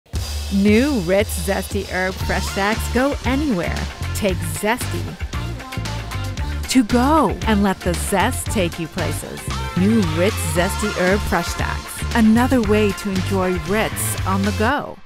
female voiceover artist
Studio Bricks Sound Booth
Commercial Spots